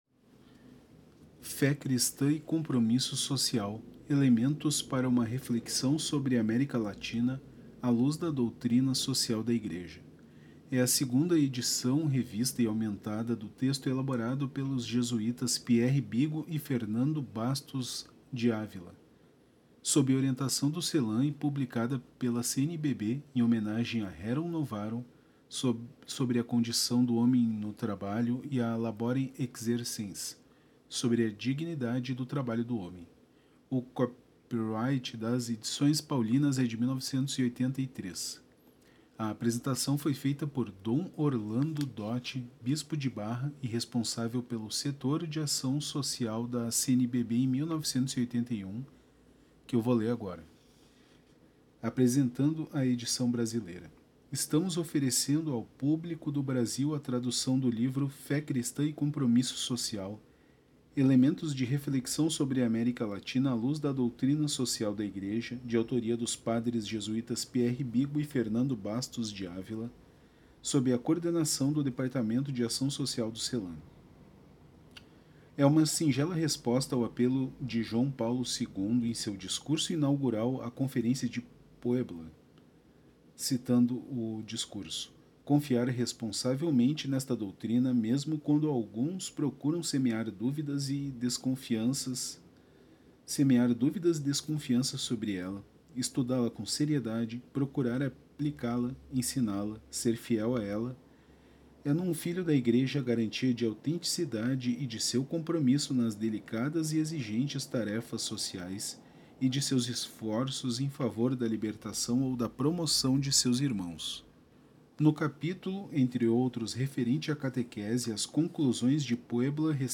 Agora vem a introdução, das páginas 9 a 17.